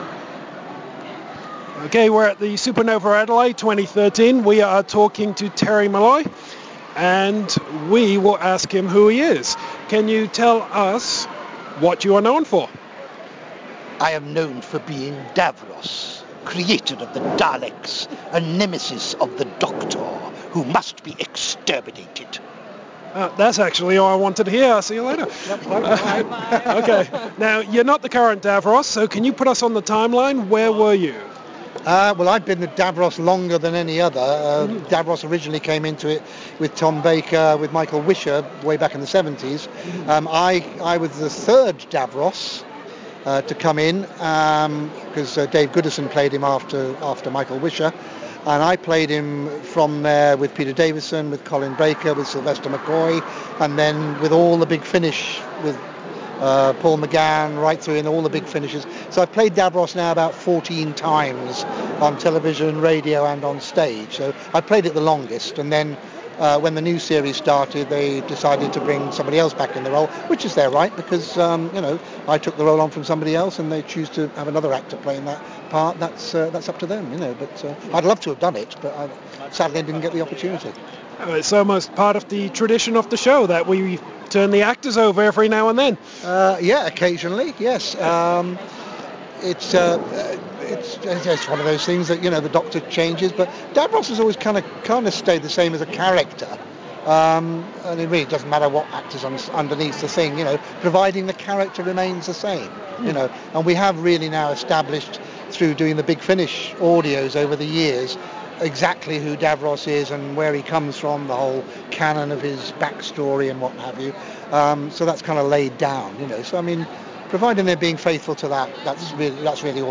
Terry Molloy – The Exclusive House of Geekery Interview!
We took some time with the man behind the mask as this years Adelaide Supanova!